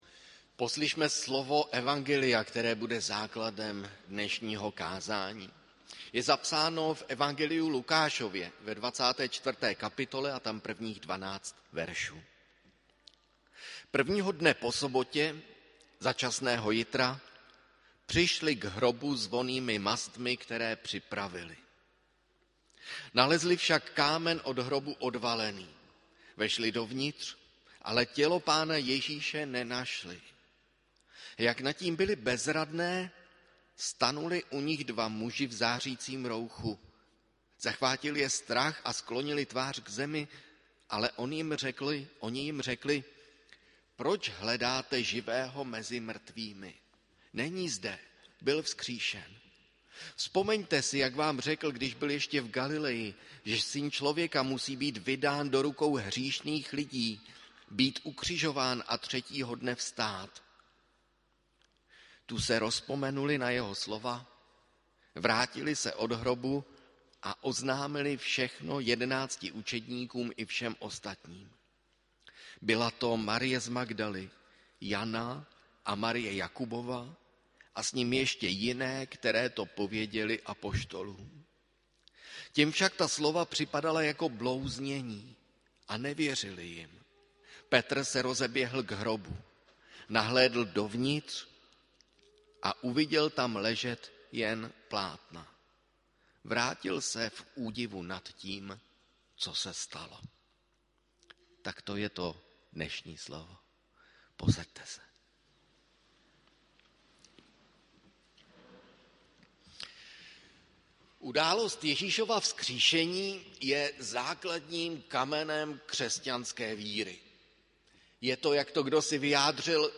Bohoslužby se slavením sv. Večeře Páně.
Kázání